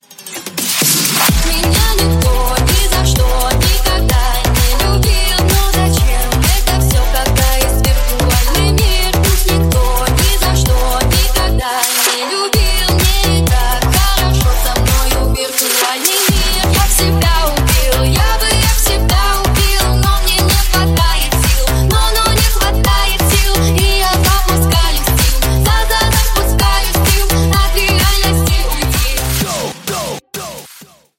Ремикс # Поп Музыка # клубные
ритмичные